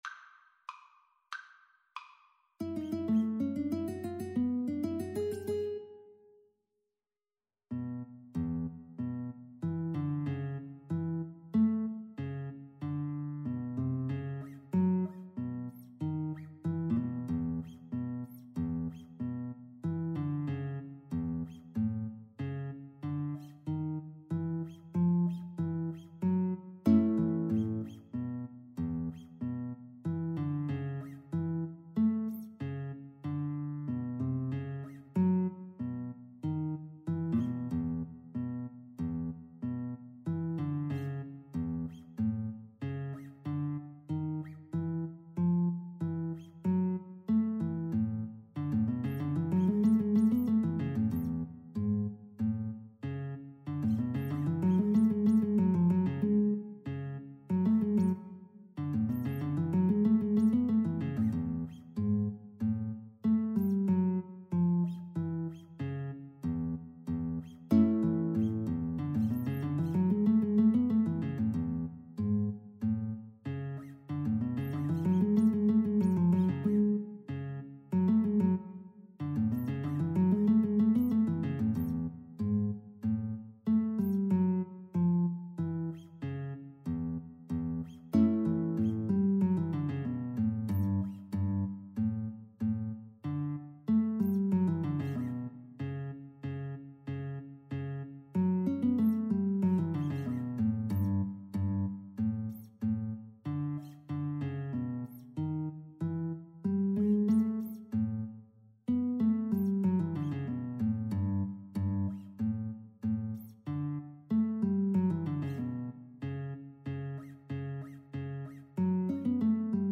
Free Sheet music for Violin-Guitar Duet
A major (Sounding Pitch) (View more A major Music for Violin-Guitar Duet )
Slow march tempo. = 94 Slow march tempo
2/4 (View more 2/4 Music)
Jazz (View more Jazz Violin-Guitar Duet Music)